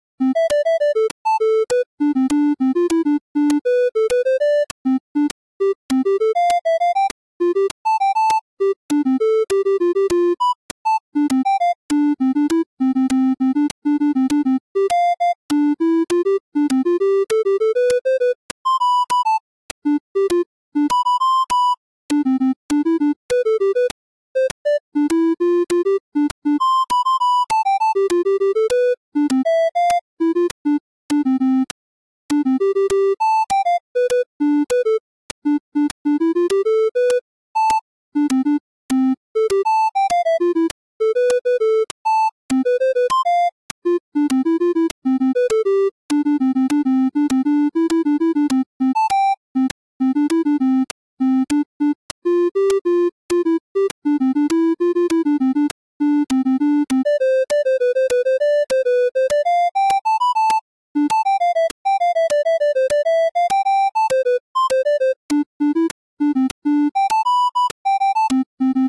By diatonic I mean taking only the notes from a ionian major scale in this case.
Here you can find a C++ code to generate silly, sometimes funny, melodies.
As you can see in the code, I limit the span to two octaves, so you will see how sometimes the algorithm insists bouncing on these two walls.
I have also added a noise instrument to serve as a metronome, marking at 2nd and 4th beats of each measure, to help following the score.